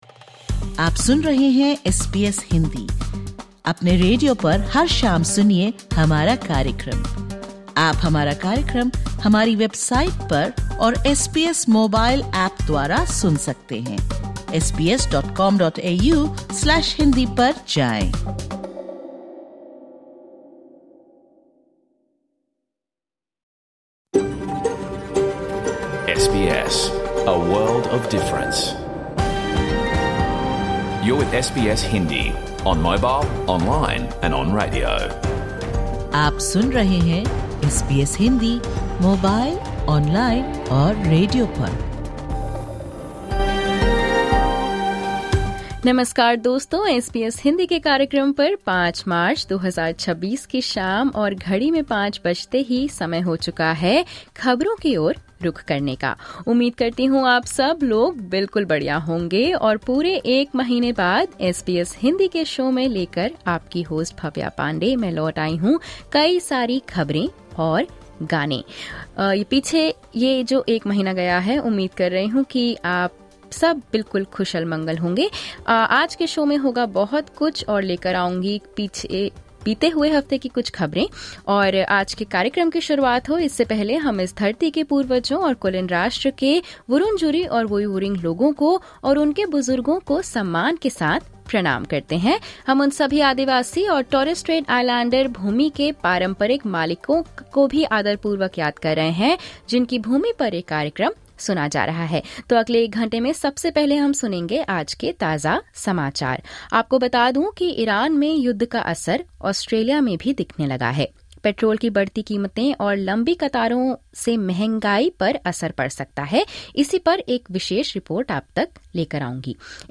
Catch the full radio program of SBS Hindi
You can also tune in to SBS Hindi at 5 pm on SBS South Asian on digital radio, on channel 305 on your television, via the SBS Audio app or stream from our website.